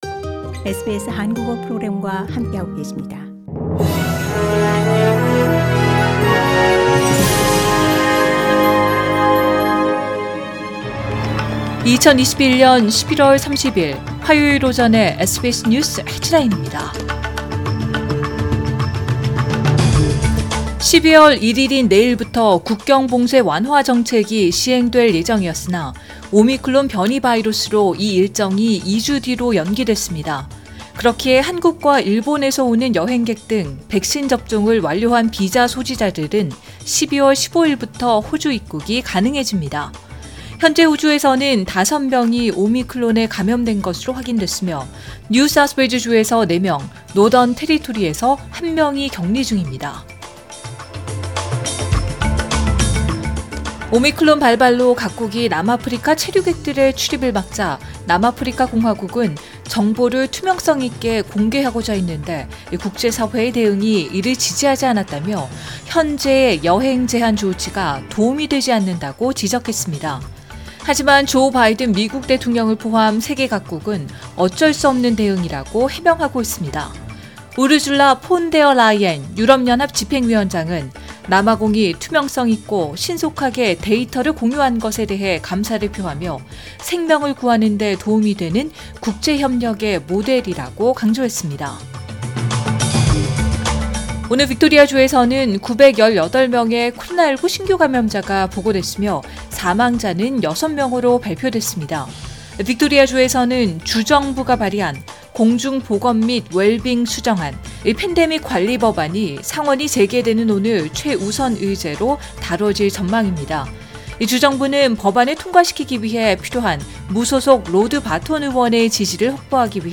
2021년 11월 30일 화요일 오전의 SBS 뉴스 헤드라인입니다.